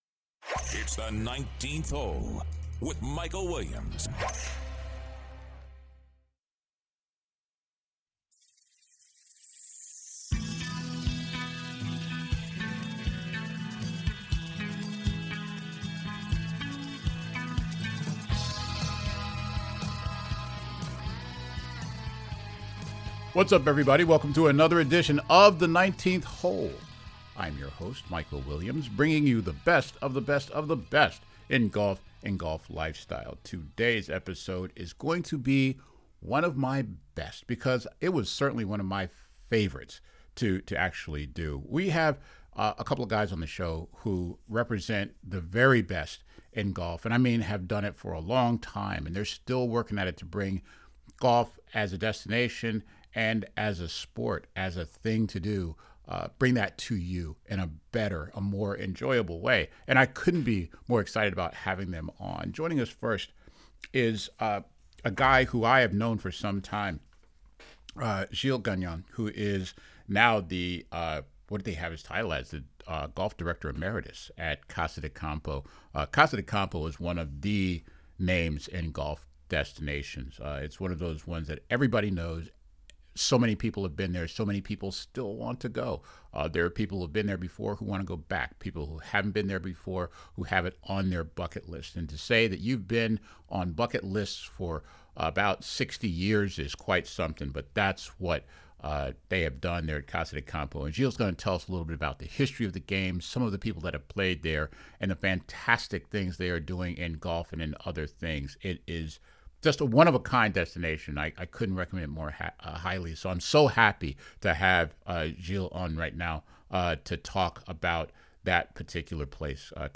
for a wonderful conversation on the past, present and future of one of the most iconic names in destination golf.